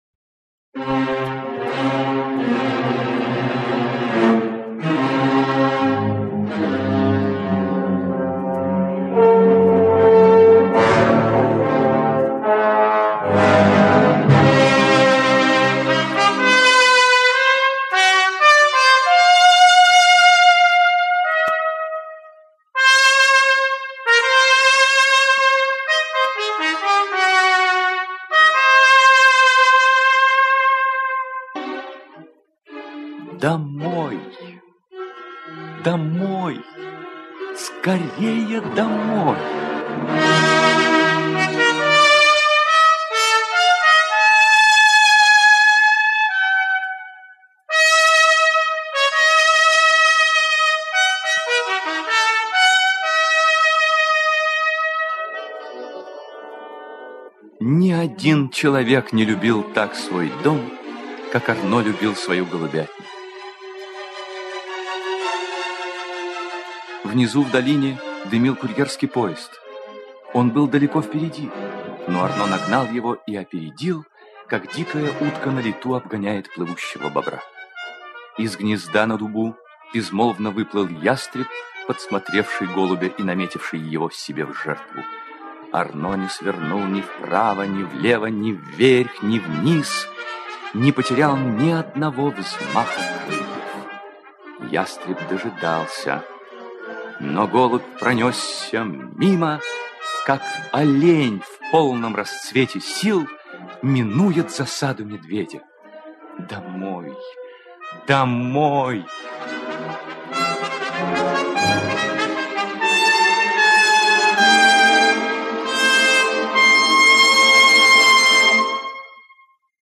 Арно - аудио рассказ Эрнеста Сетона-Томпсона - слушать онлайн